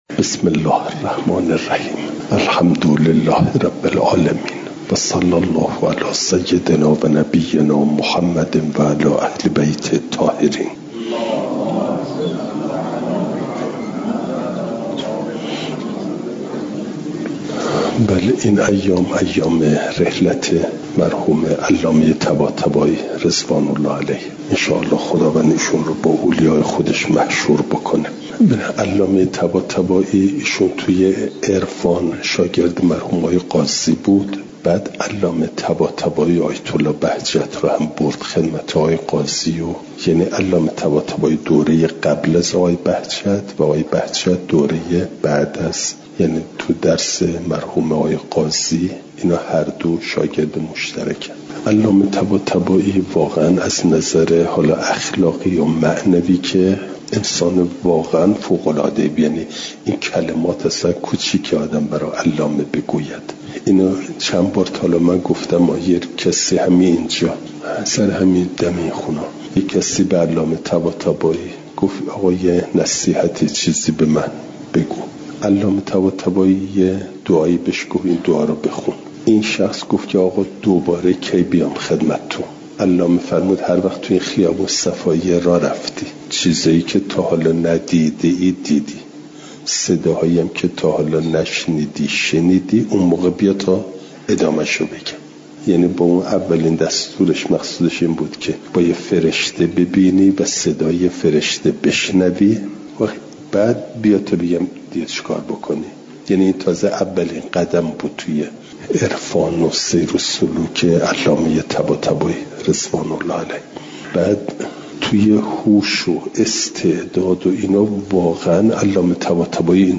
شنبه ۲۴ آبانماه ۱۴۰۴، دارالقران علامه طباطبایی(ره)